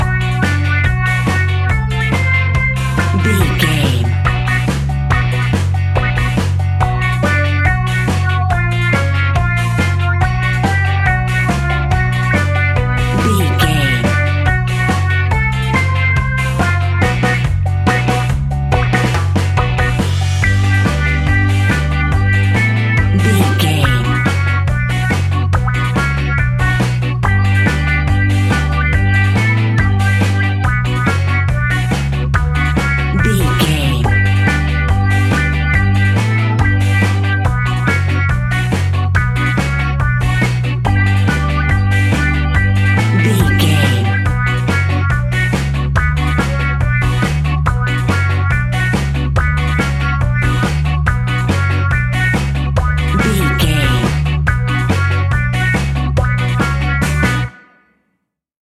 Aeolian/Minor
reggae
laid back
chilled
off beat
drums
skank guitar
hammond organ
percussion
horns